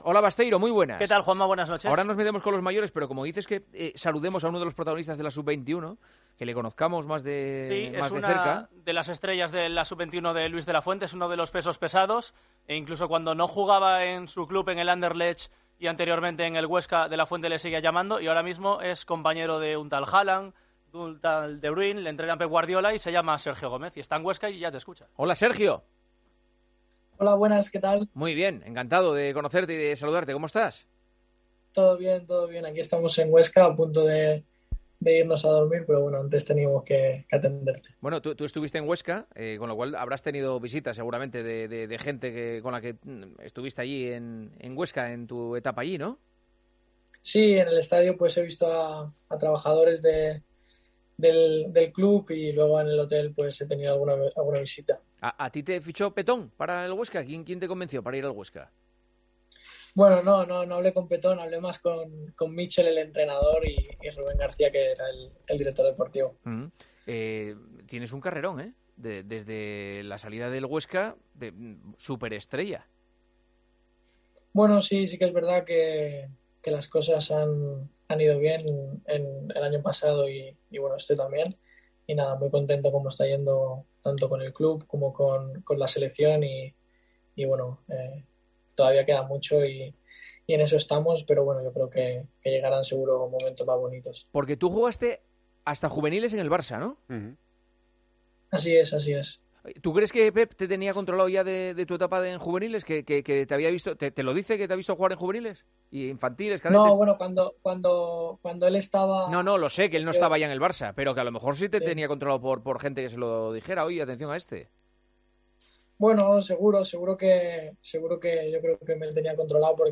AUDIO: El futbolista del Manchester City ha atendido a El Partidazo de COPE desde la concentración de la selección española sub-21.